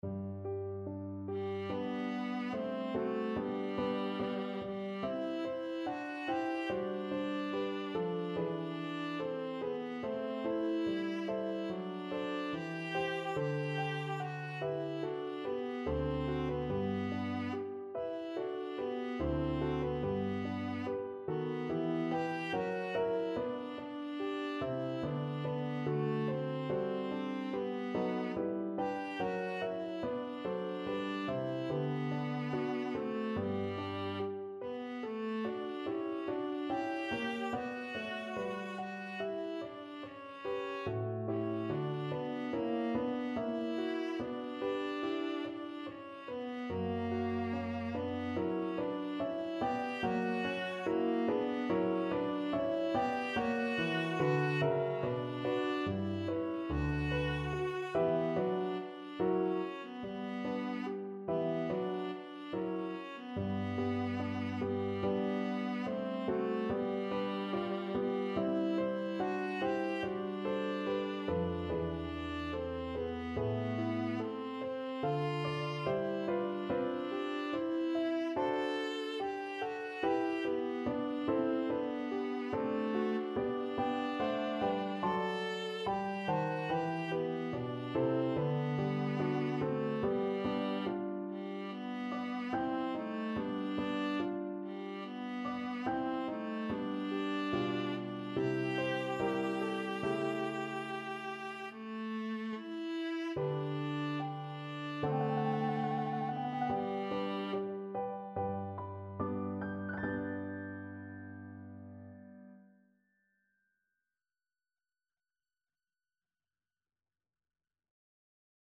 4/4 (View more 4/4 Music)
Andantino = 72 (View more music marked Andantino)
Classical (View more Classical Viola Music)